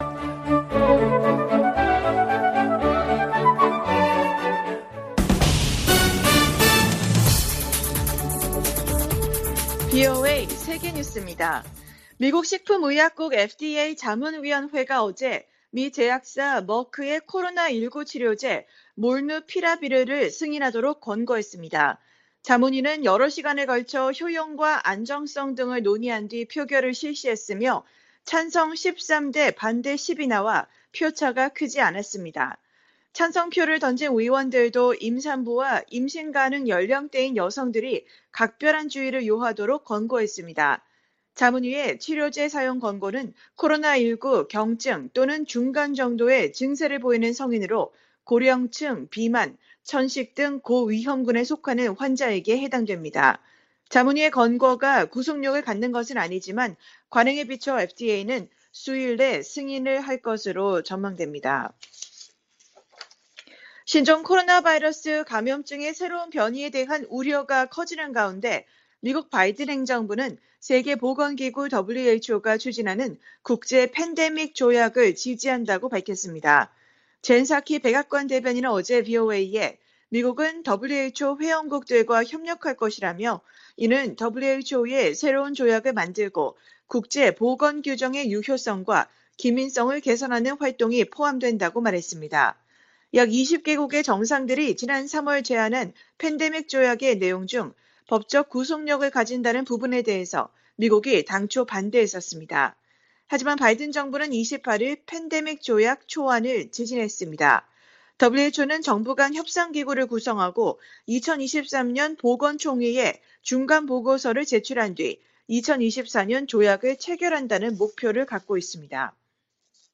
VOA 한국어 간판 뉴스 프로그램 '뉴스 투데이', 2021년 12월 1일 2부 방송입니다. 11월 중에 재개될 가능성이 거론됐던 북-중 국경 개방이 무산된 것으로 보입니다. 올해는 9년 만에 미국의 대북 독자 제재가 한 건도 나오지 않은 해가 될 가능성이 높아졌습니다. 한국전 실종 미군 가족들이 미국 정부에 유해 발굴 사업을 정치적 사안과 별개로 추진하라고 요구했습니다.